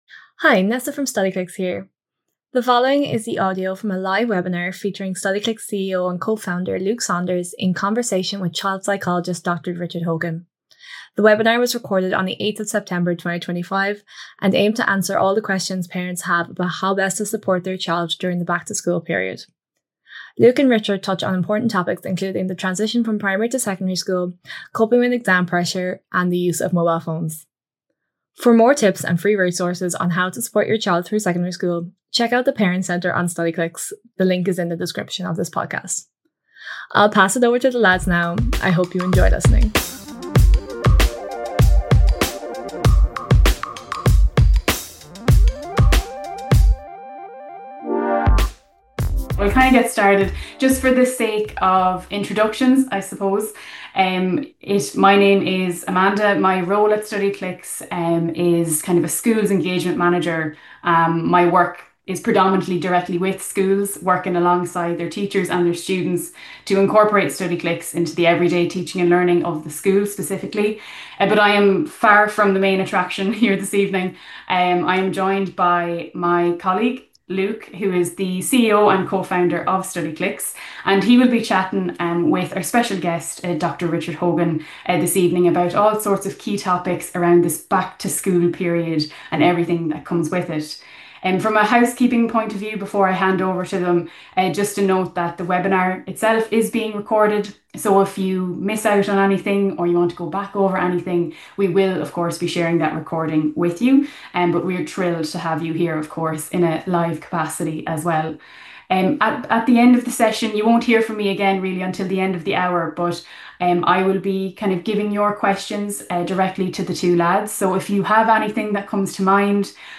This is the audio recording from that webinar.